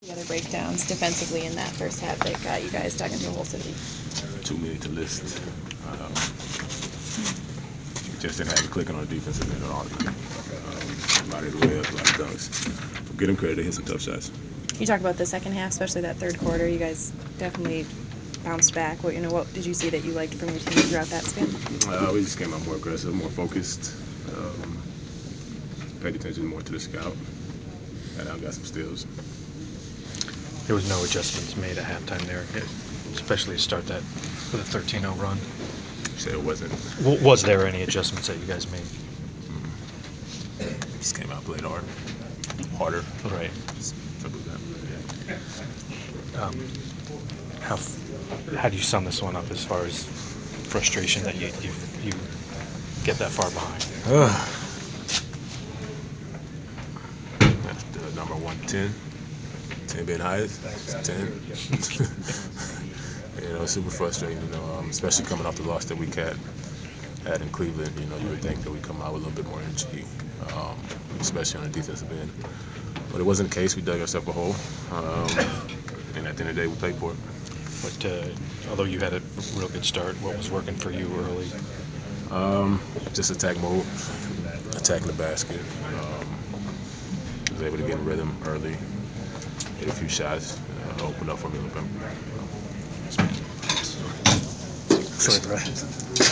Inside the Inquirer: Postgame interview with Atlanta Hawk Paul Millsap 11/18/14